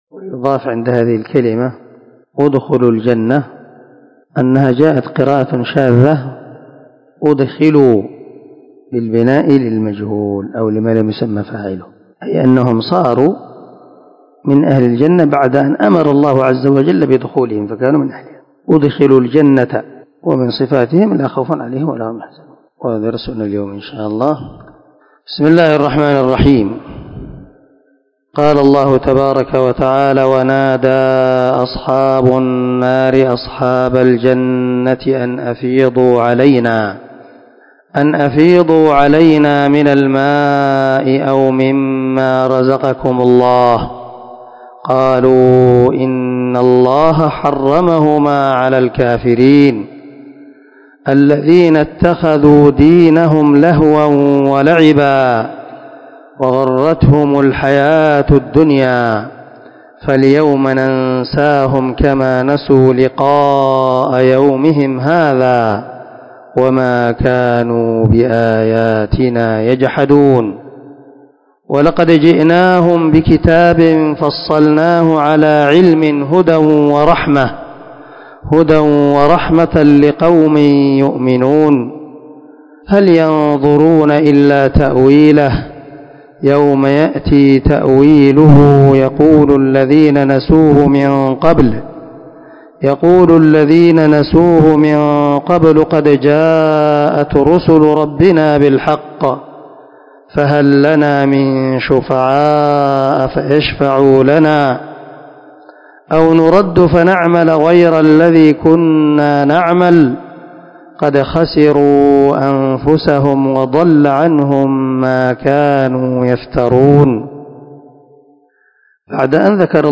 465الدرس 17 تفسير آية ( 50 – 53 ) من سورة الأعراف من تفسير القران الكريم مع قراءة لتفسير السعدي